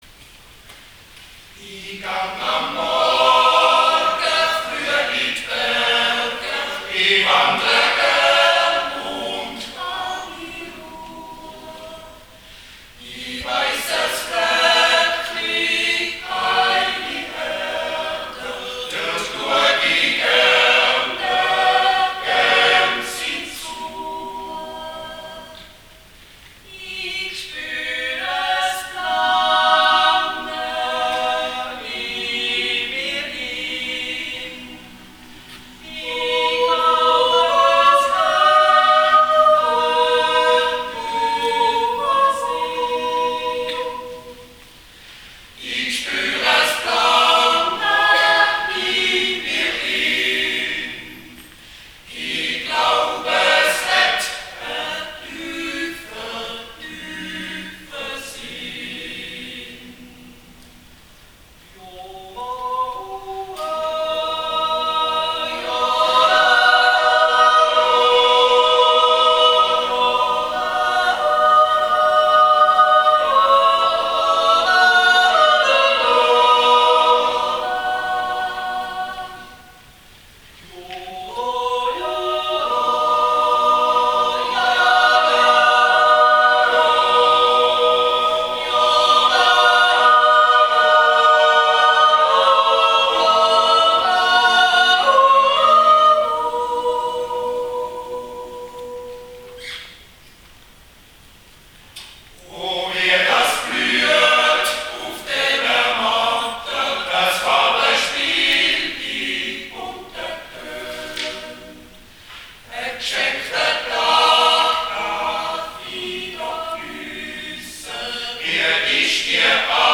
Jodlerfest Interlaken - ein schönes Fest ist bereits Geschichte
Mit unserem Liedvortrag "s'Plange" ersangen wir die Note "Gut".